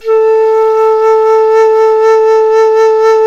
Index of /90_sSampleCDs/INIS - Opium/Partition H/DIZU FLUTE
DIZI01A2.wav